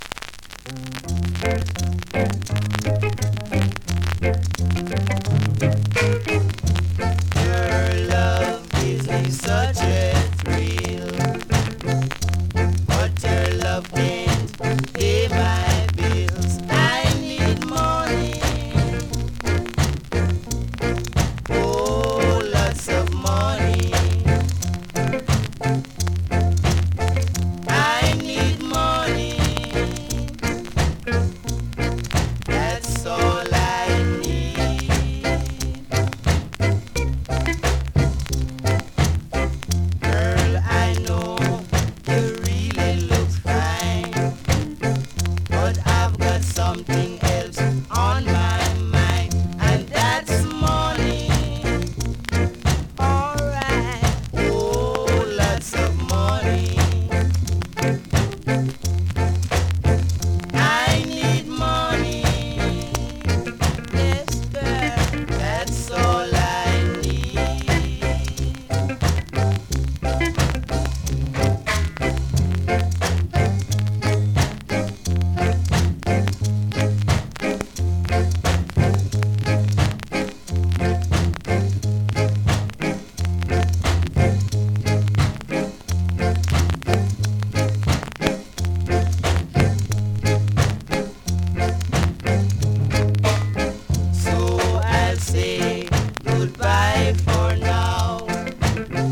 スリキズ、ノイズそこそこあります。